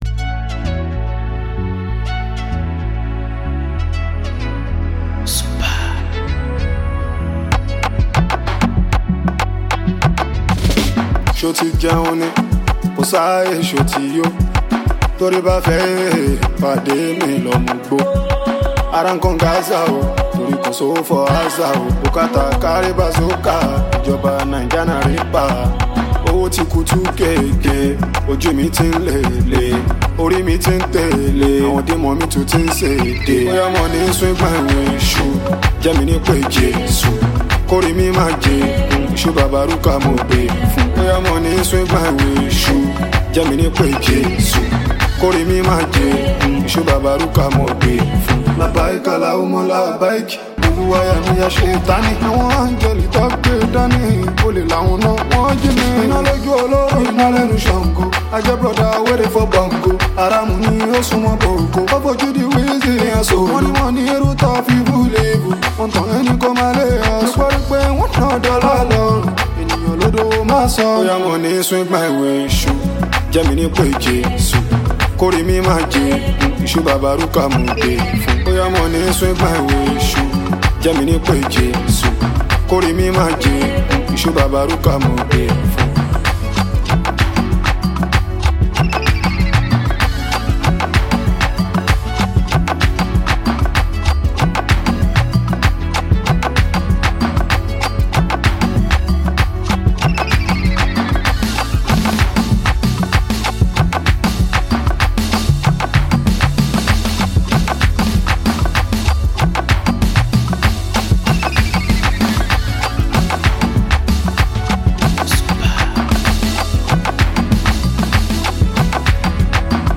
If you’re a lover of captivating music and dynamic beats
Afrobeats